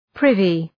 Προφορά
{‘prıvı}